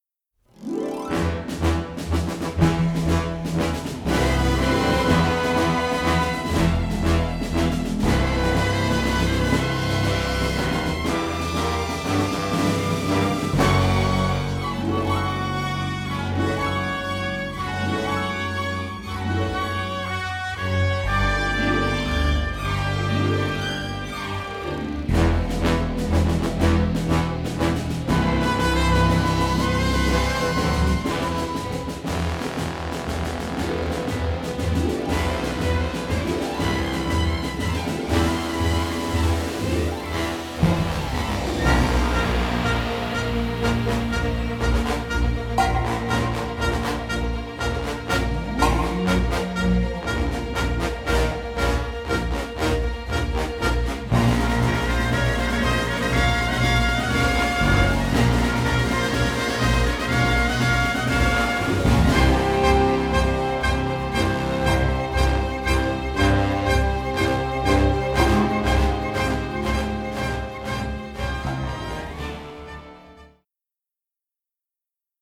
all in splendid stereo sound.
orchestra